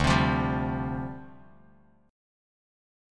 Dm11 Chord
Listen to Dm11 strummed